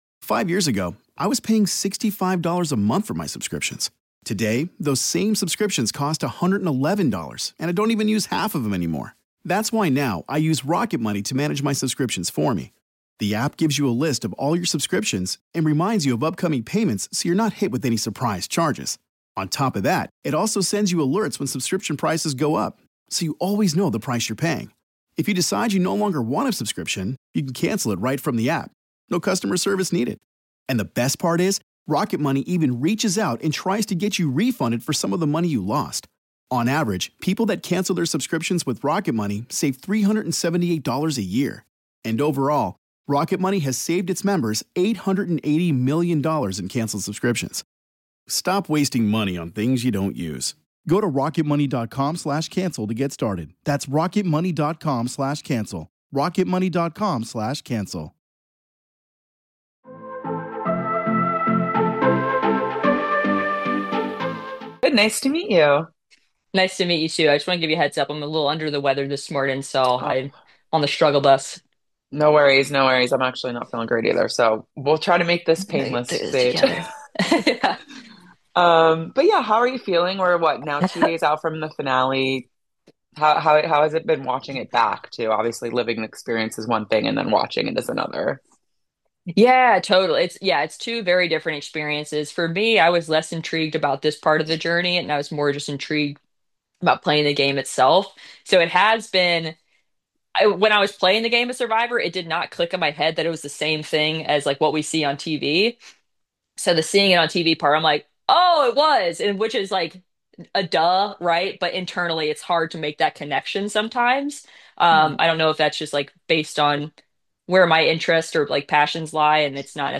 Survivor 49 Exit Interview